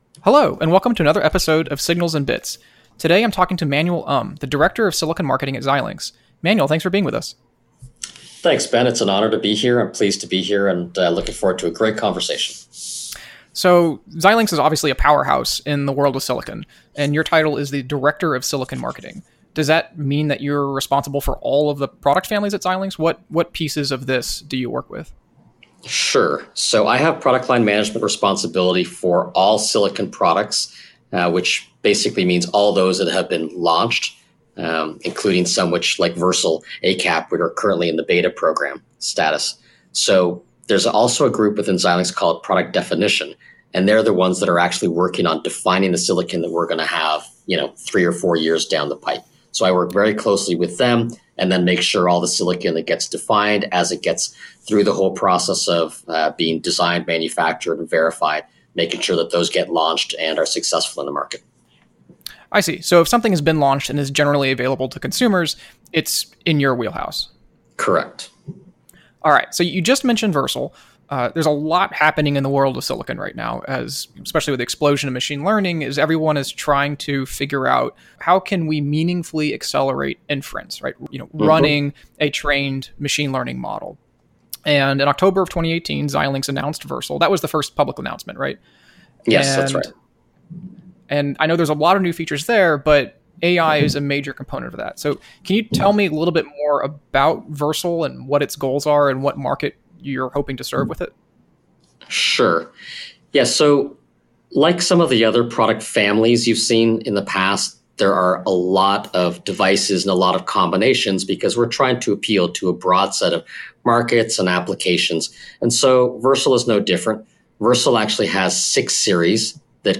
Play Rate Listened List Bookmark Get this podcast via API From The Podcast Interviews with amazing people about software radio, the technology behind it, and everything it makes possible.